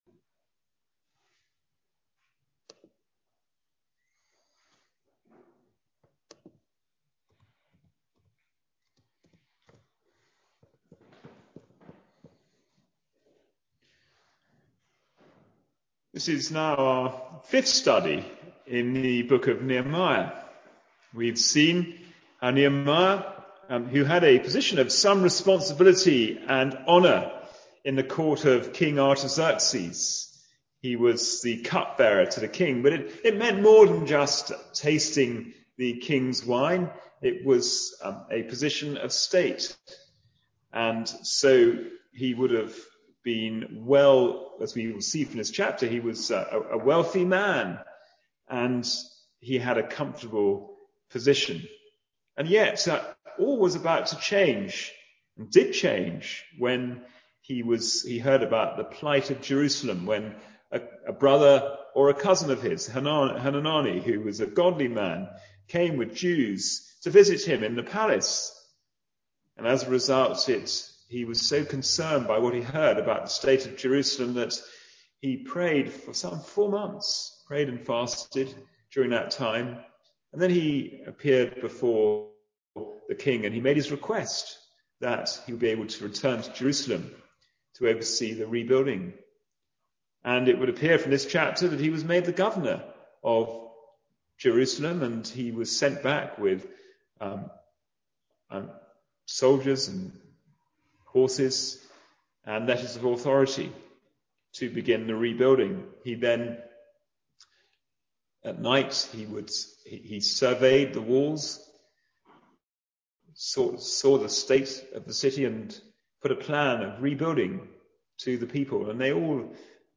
Passage: Nehemiah 5 Service Type: Sunday Morning Service